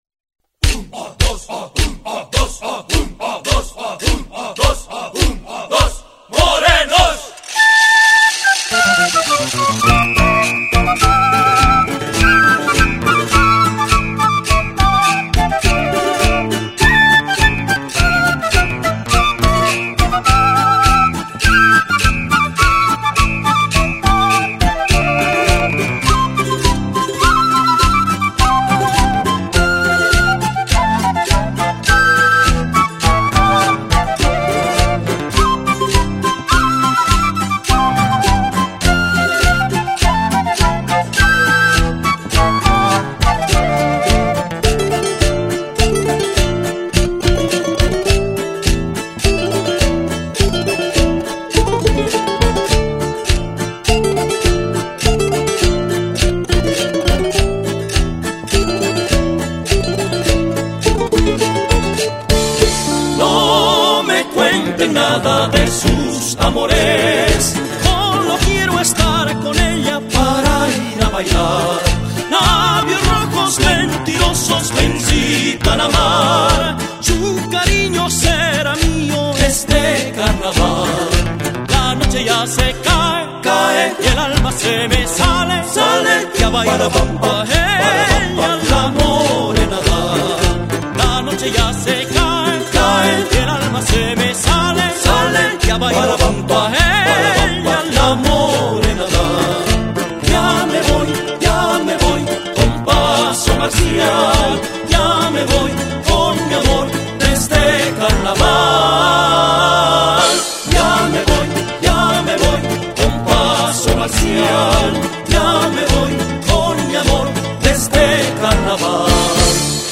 [Grupos Folkloricos] - [Solicitud de Presentaciones]
1er tenor
2do tenor, percusión
Baritono, vientos
Voz baja, guitarra
Charangos
Bajo electrónico